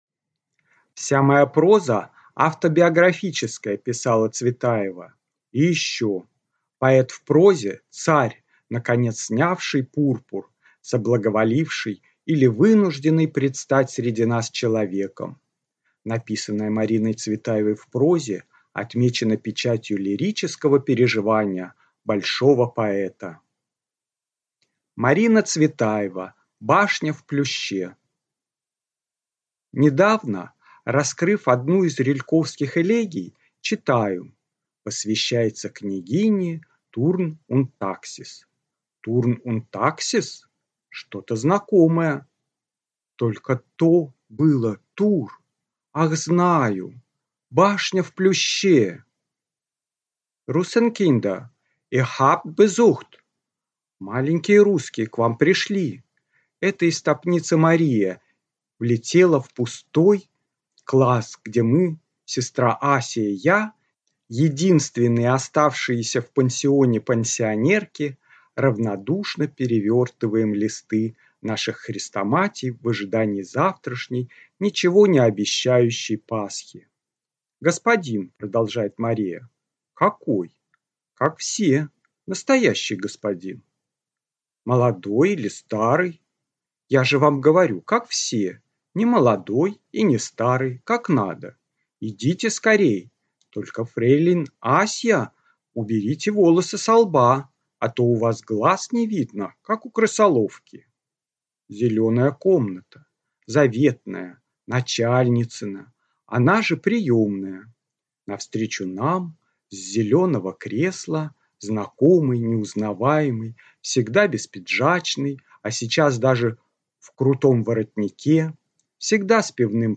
Аудиокнига Башня в плюще | Библиотека аудиокниг